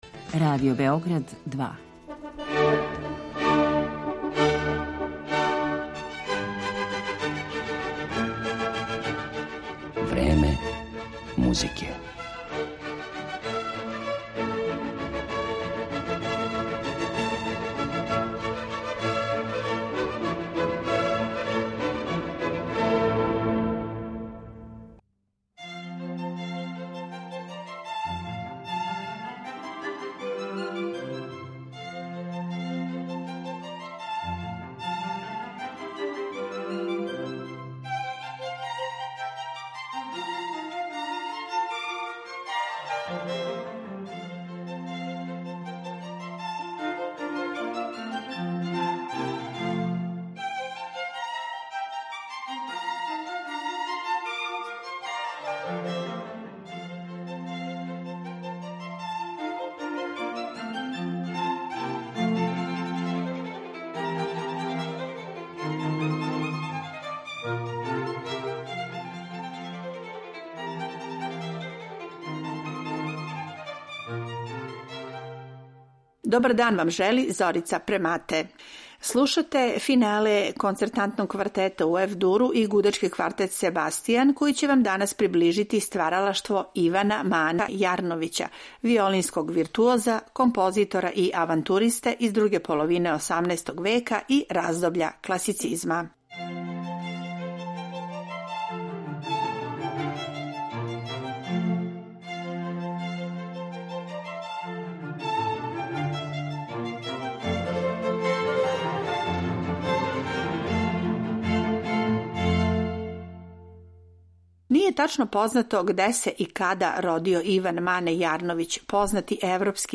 концертне квартете и концерте за виолину.